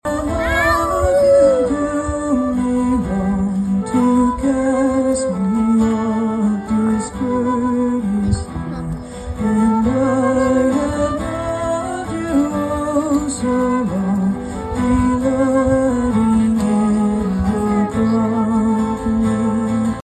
Three homes served as concert venues for Emporia’s annual Front Porch Music Festival on Saturday.
3810-jam-1.mp3